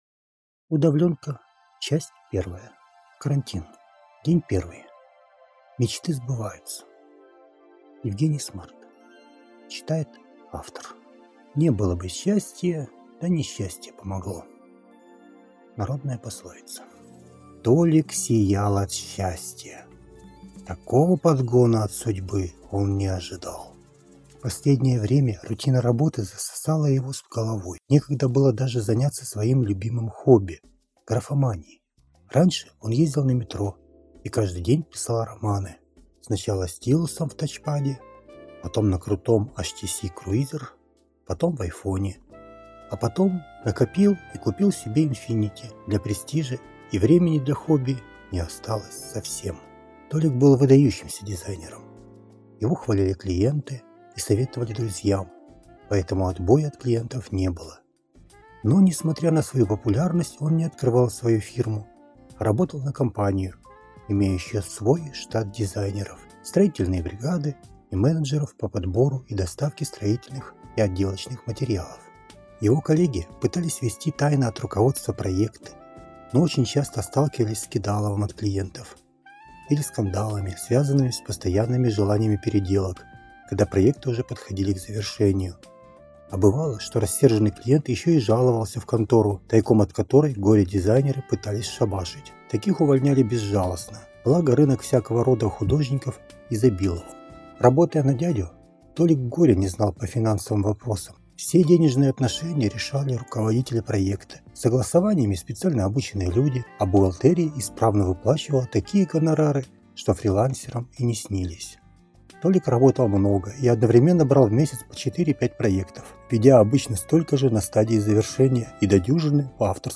Аудиокнига УдаВлёнка. Полная версия. Весна'20 | Библиотека аудиокниг